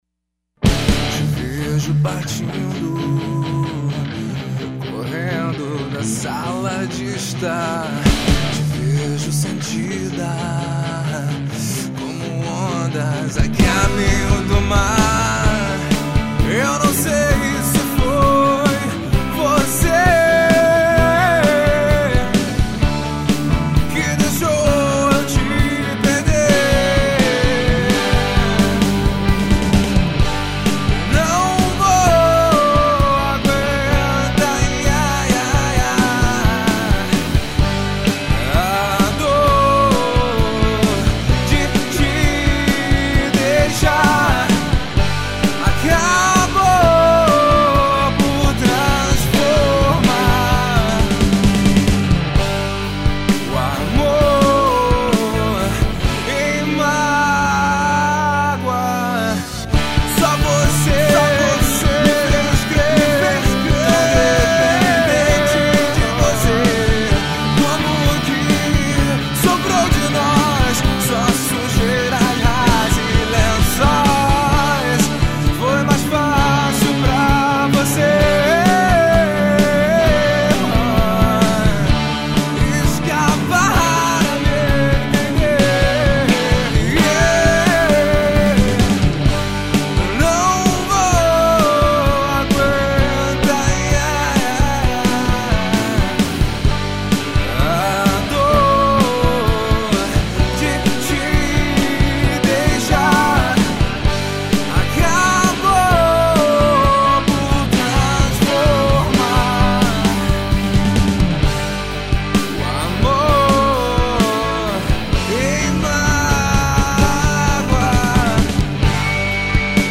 EstiloGrunge